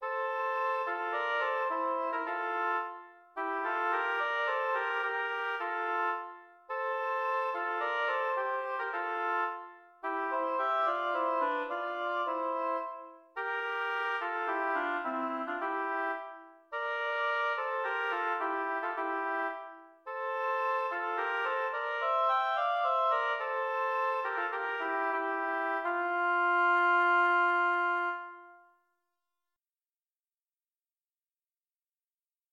Instrumentation: oboe duet
tags: oboe music